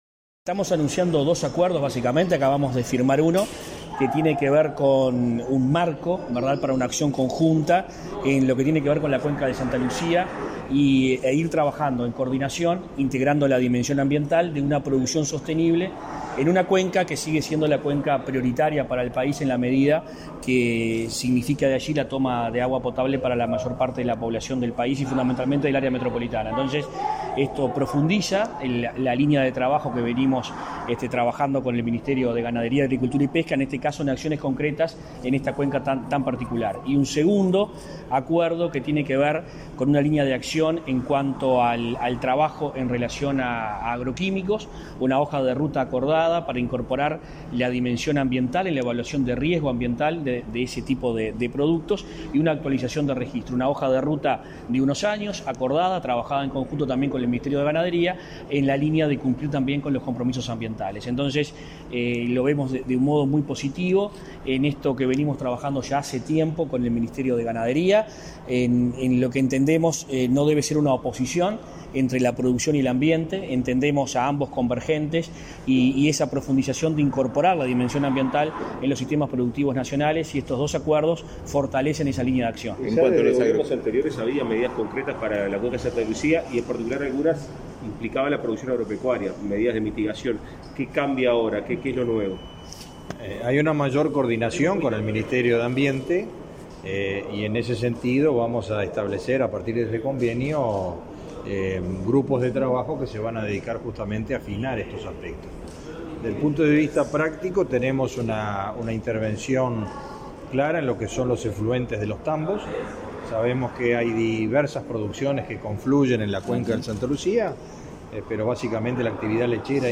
Declaraciones a la prensa de los ministros de Ambiente, Adrián Peña, y Ganadería, Fernando Mattos
Declaraciones a la prensa de los ministros de Ambiente, Adrián Peña, y Ganadería, Fernando Mattos 17/11/2021 Compartir Facebook X Copiar enlace WhatsApp LinkedIn Los ministerios de Ambiente y Ganadería firmaron este miércoles 17 un convenio para el desarrollo agropecuario sostenible en la cuenca del río Santa Lucía, que implica mecanismos de cooperación conjunta. Tras la firma, los ministros Adrián Peña y Fernando Mattos efectuaron declaraciones a la prensa.